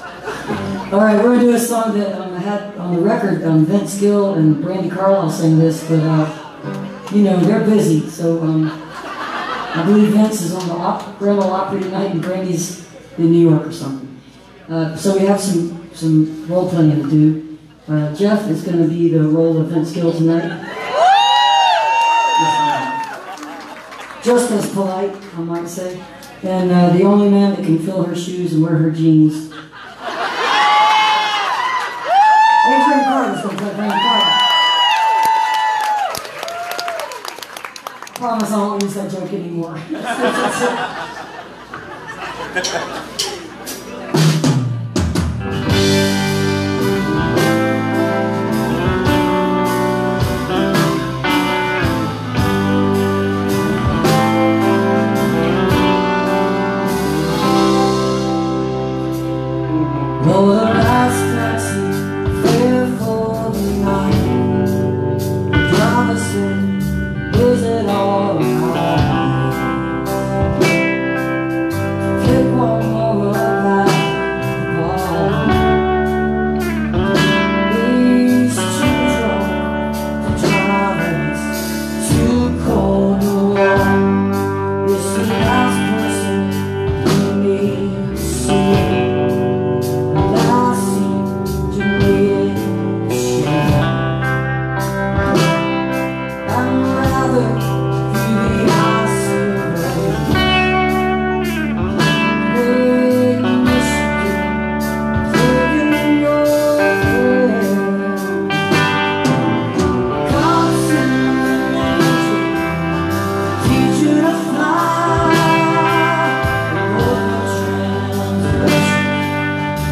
(captured from facebook live stream)